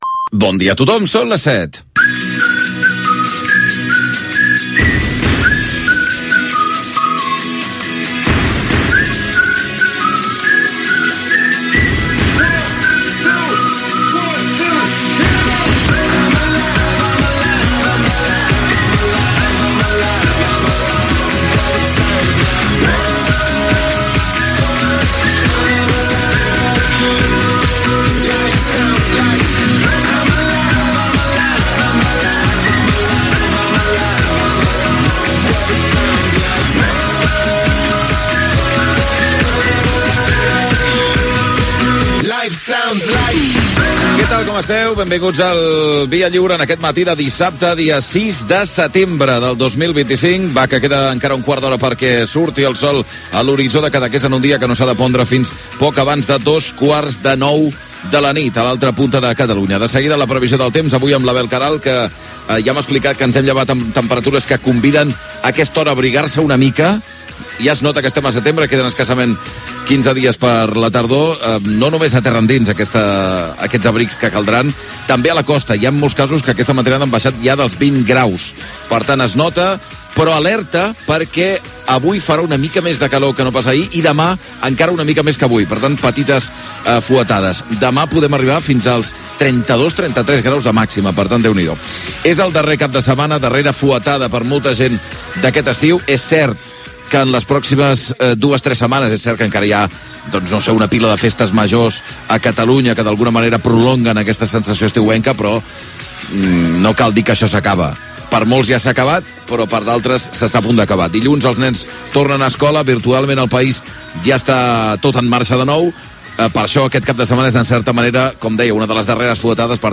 Inici del primer programa de la temporada 2025-2026. Hora, sintonia, salutació, data, presentació i sumari.